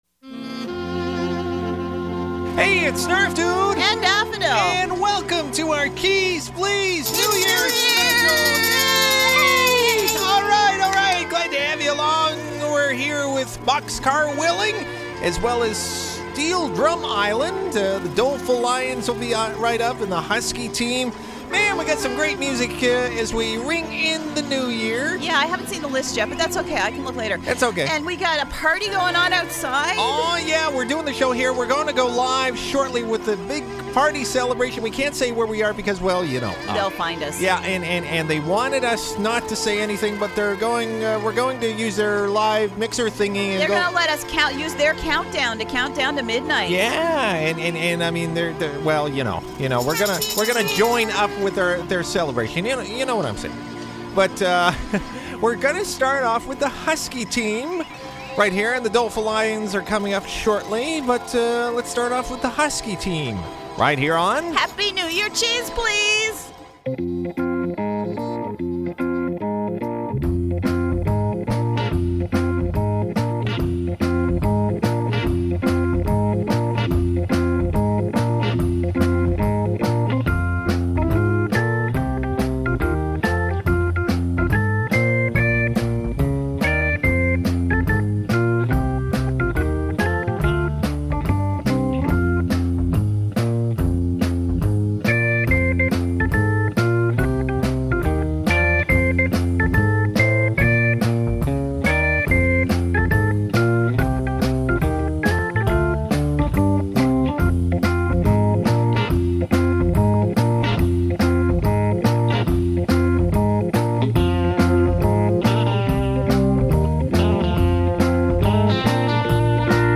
Auld Lang Syne (DISCO VERSION)
SFX fireworks